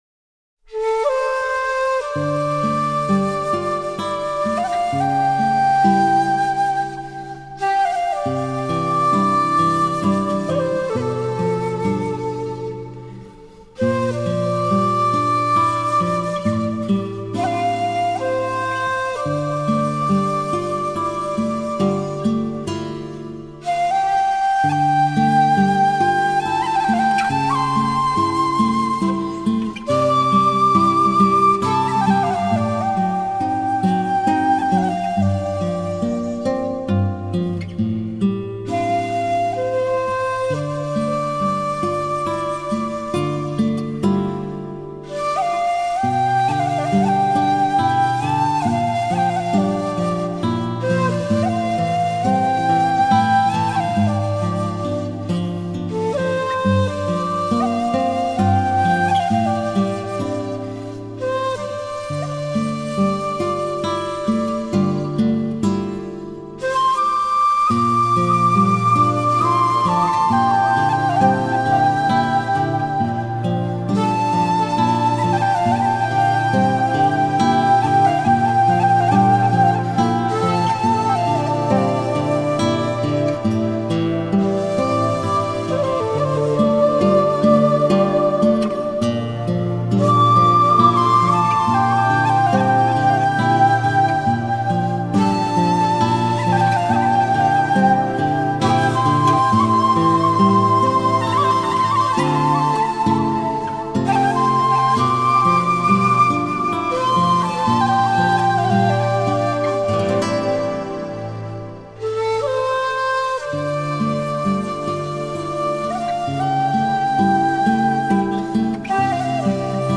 熱きサムライ魂が響きわたる
尺八、和太鼓、箏など、日本の伝統楽器と最新のサウンドで和の文化を伝えるコンピレーション・アルバム。
尺八、太鼓、古筝等日本传统乐器和最新的声音和传播文化汇编专辑。